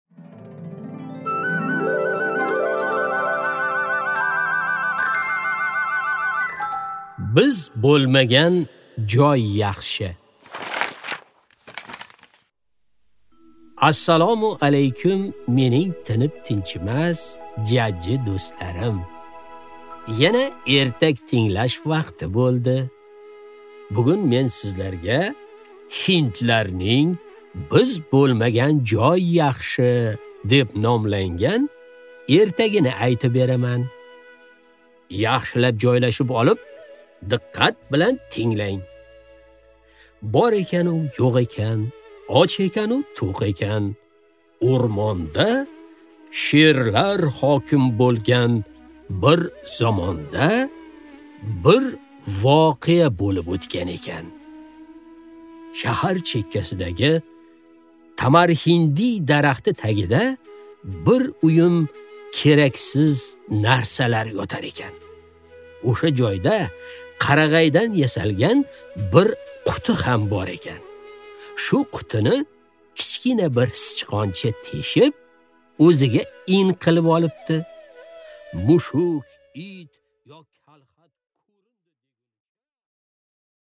Аудиокнига Biz bo'lmagan joy yaxshi | Библиотека аудиокниг